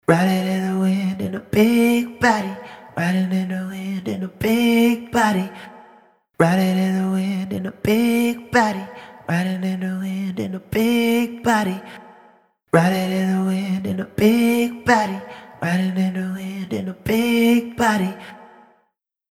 Octavox | Vocals | Preset: 4 Vox Detune
Octavox-Eventide-Male-Vocal-4-Vox-Detune-2.mp3